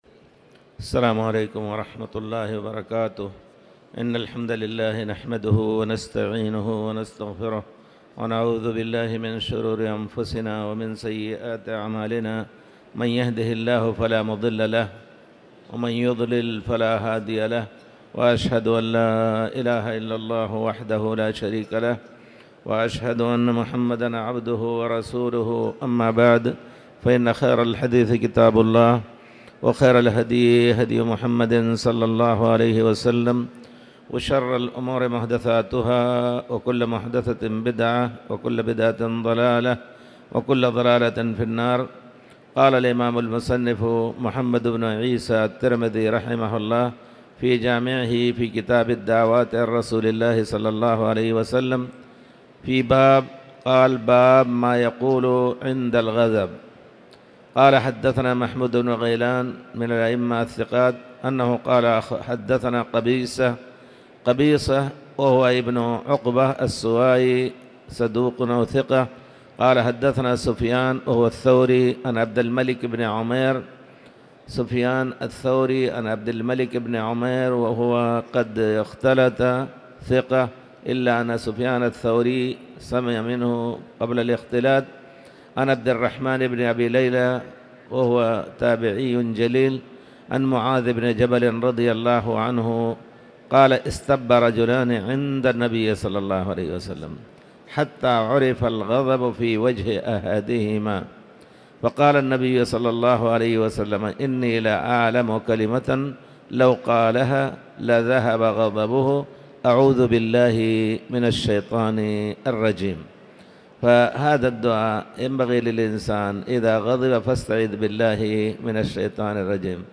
تاريخ النشر ٢٠ جمادى الأولى ١٤٤٠ هـ المكان: المسجد الحرام الشيخ